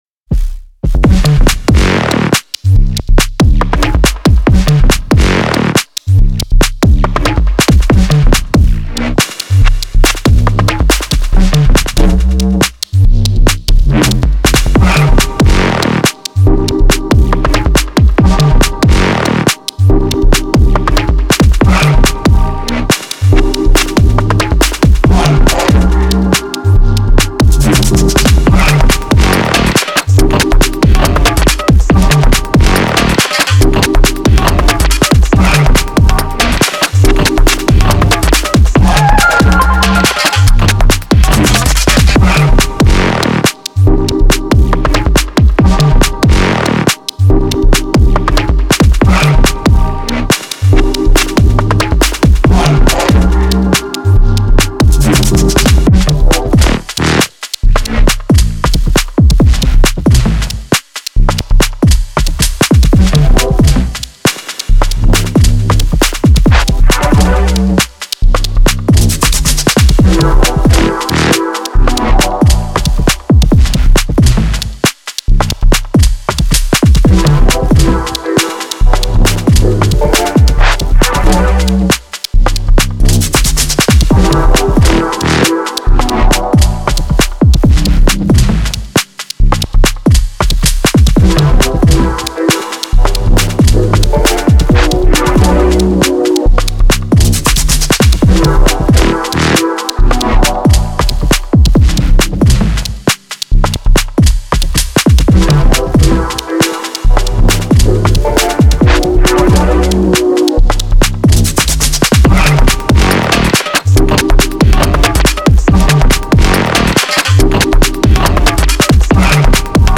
Dtn2///live - morning jam. I am low on energy last few days, so this one looks like a good “bed” for something.
Edit: Added master plan for additional 2dB.